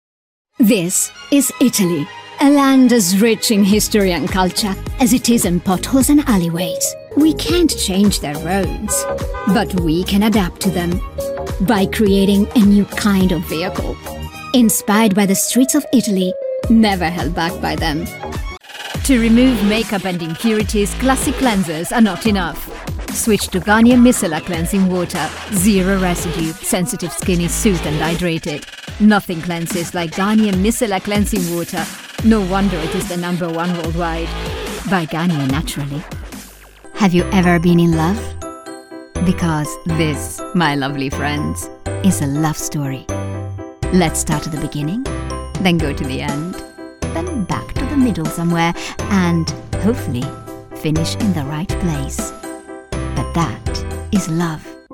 European, Italian, Female, Home Studio, Teens-30s